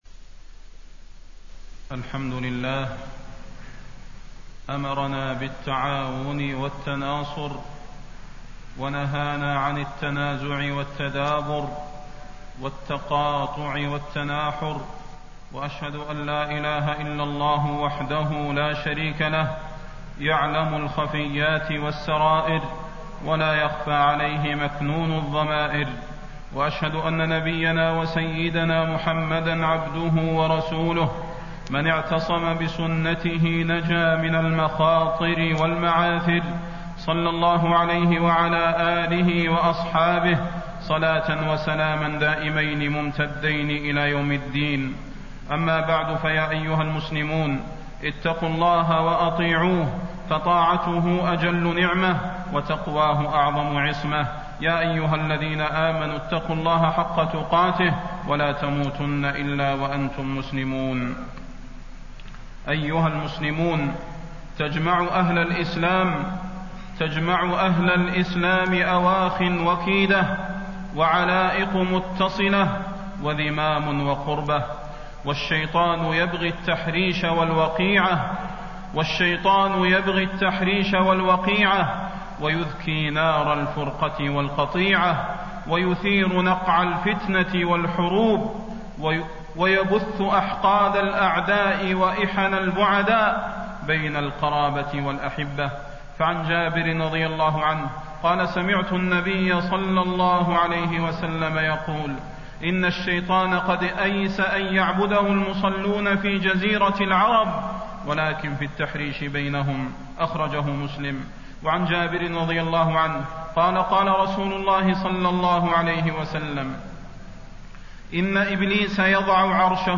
تاريخ النشر ٢٠ جمادى الآخرة ١٤٣٣ هـ المكان: المسجد النبوي الشيخ: فضيلة الشيخ د. صلاح بن محمد البدير فضيلة الشيخ د. صلاح بن محمد البدير تحذير الأمة من الوقيعة بين الأحبة